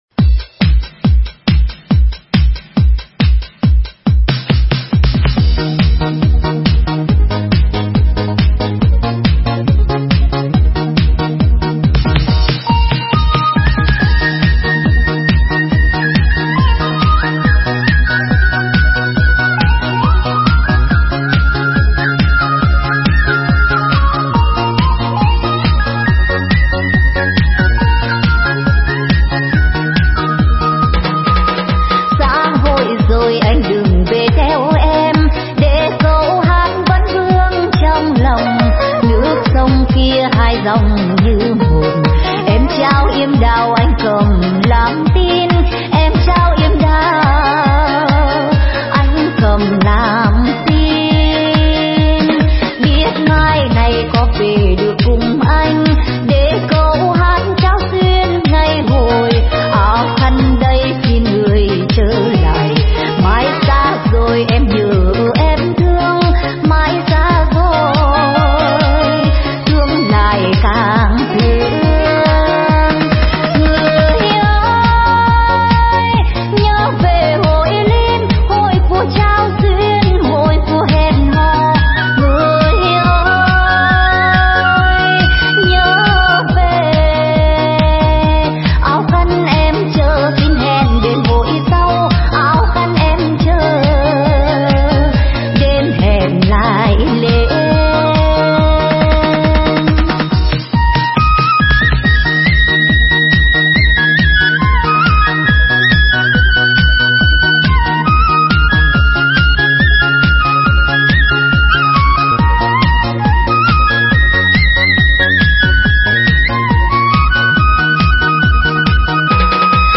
Nhạc Dân Tộc Remix